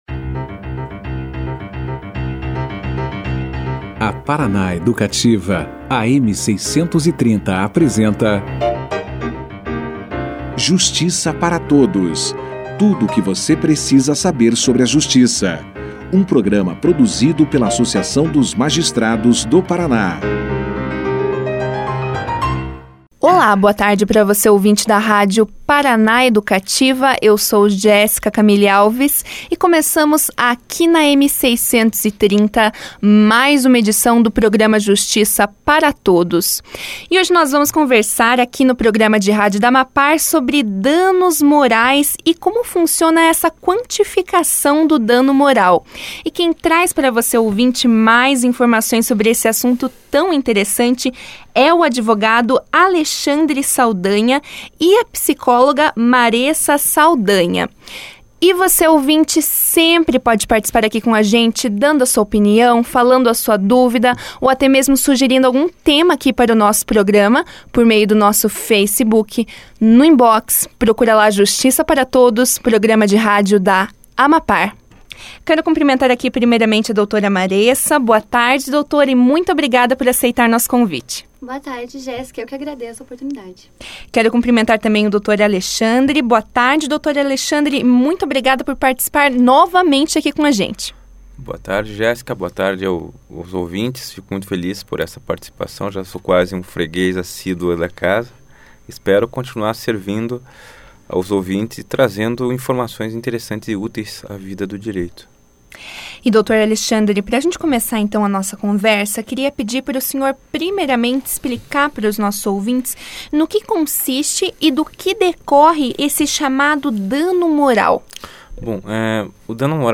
O que são os danos morais, situações em que existe o dever de indenização e como pode-se provar e quantificar os prejuízos à vítima em uma ação de danos morais, foram questões esclarecidas logo no início da entrevista pelos convidados.